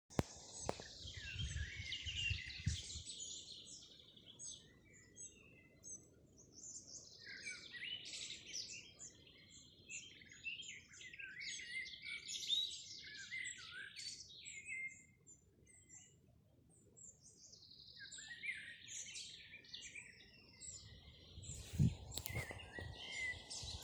Thrush Turdus sp., Turdus sp.
StatusSinging male in breeding season
NotesOpekalna kapu lielajos kokos